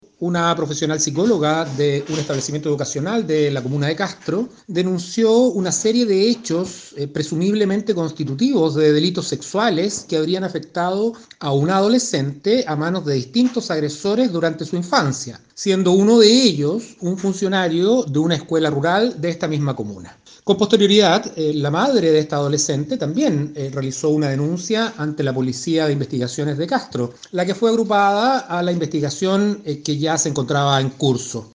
La fiscalía de Castro inició una investigación tras una denuncia que apunta a un funcionario de una escuela municipal de Castro por un presunto abuso sexual. El fiscal jefe de Castro, Enrique Canales, explicó que los antecedentes puestos en conocimiento del ministerio público dan cuenta que el delito habría sido cometido en contra de una menor de edad, asunto que se encuentra bajo investigación.